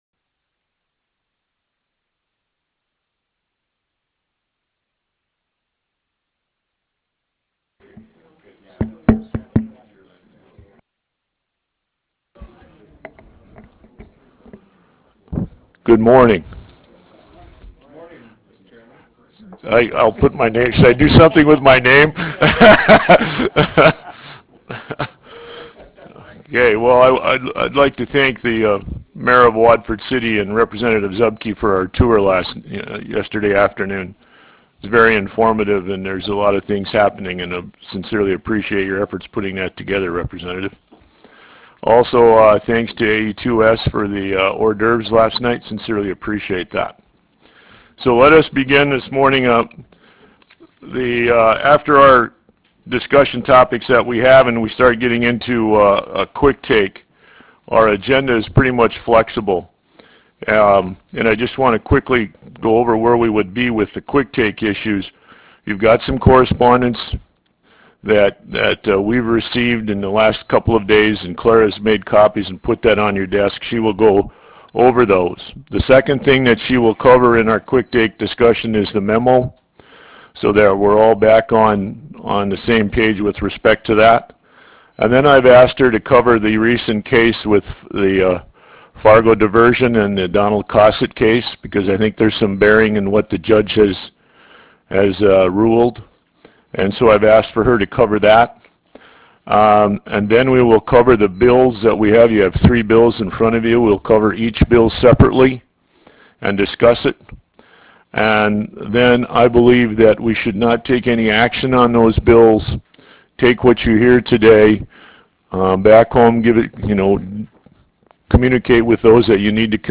Grand Ranch Room Teddy's Residential Suites 113 9th Avenue SE Watford City, ND United States
Meeting Audio